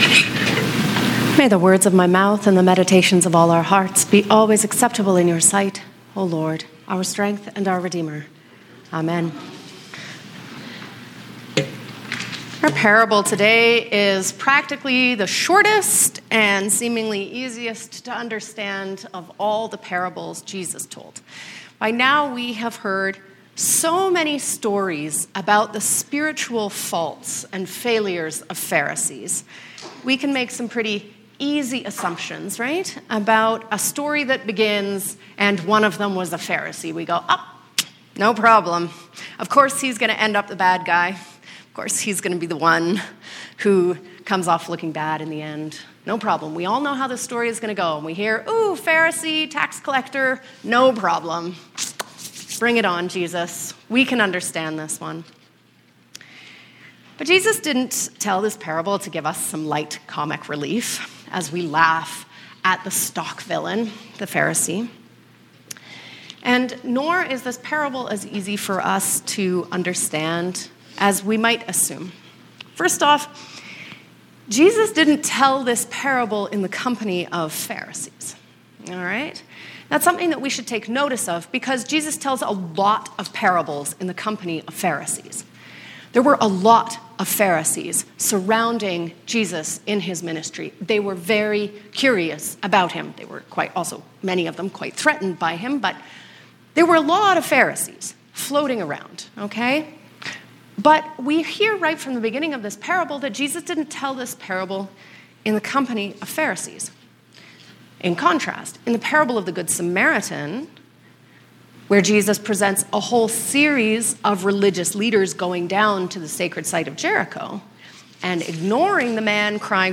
Sermons | St. George's Anglican Church